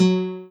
noteblock_guitar.wav